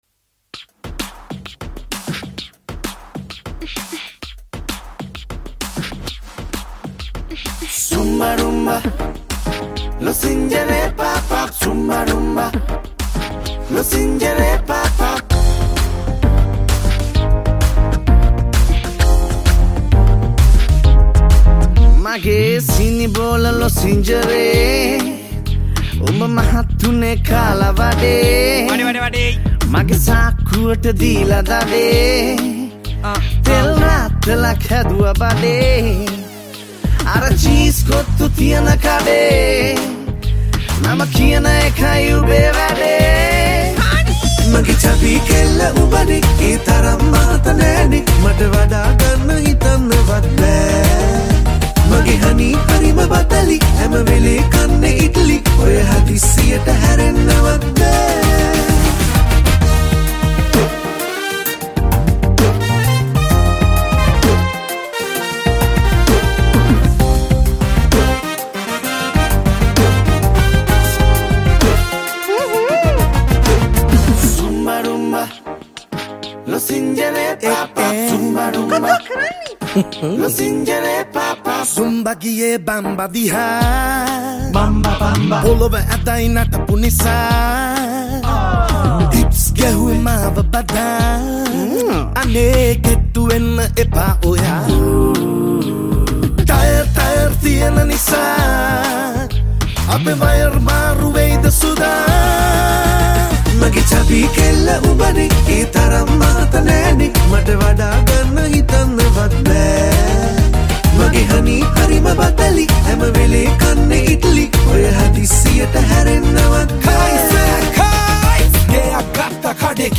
Rap Lyrics & Rap Vocals